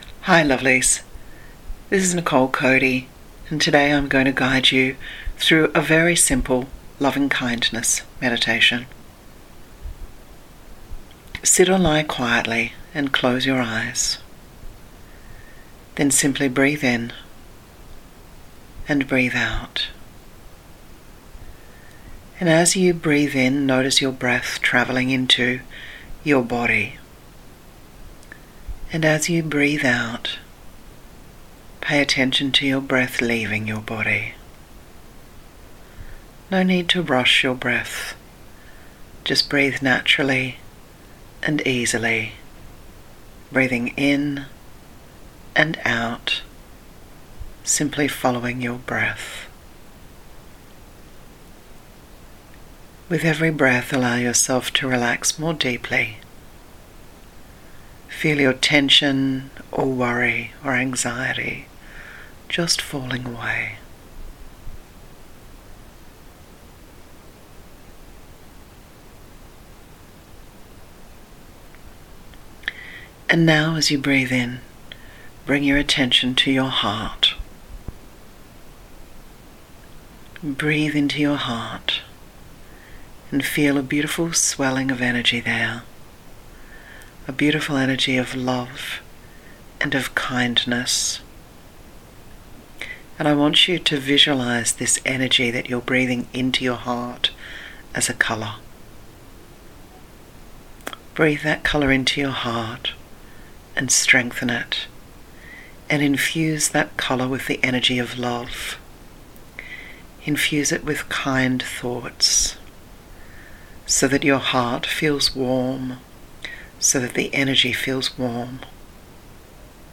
Guided Meditation
It’s a simple, gentle meditation that begins with you — breathing kindness and compassion into your own heart — and then gently expands outward, flowing that warmth toward someone you love, and then out into the world.